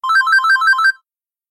Alarm_Beep03.ogg